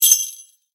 OpenHat [Skrt].wav